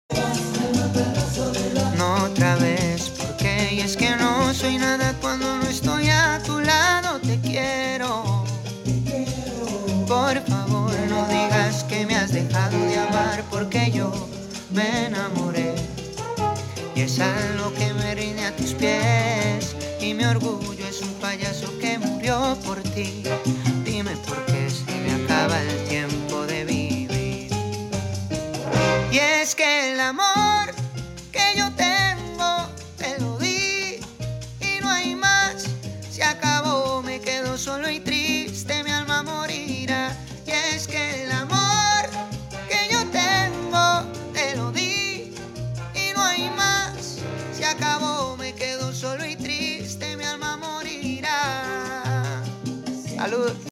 es acapela y suena bien...